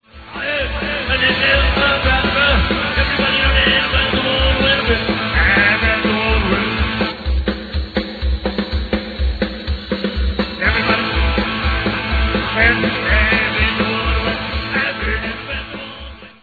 FAçA DOWLOAD DE PEDAçOS DE UMAS MÚSICAS AO VIVO!!!
GUITARA
BAIXO
BATERA